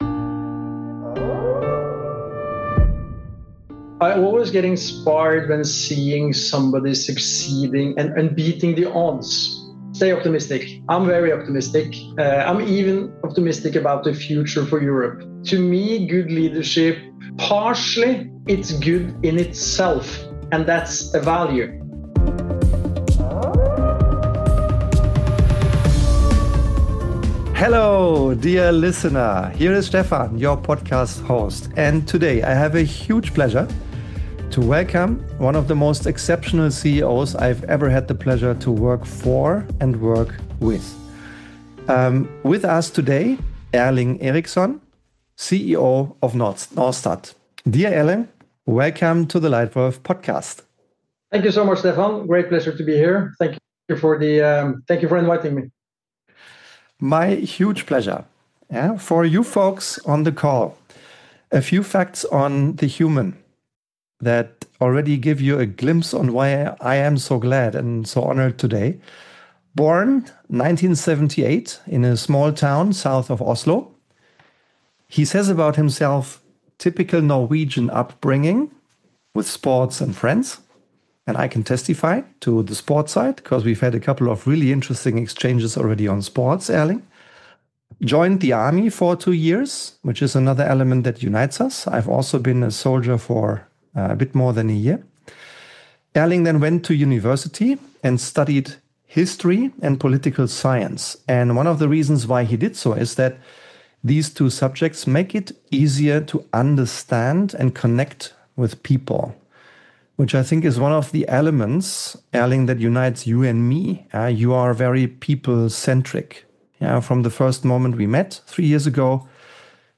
A clear and personal conversation about modern leadership, speed with substance, and the mindset that enables sustainable success.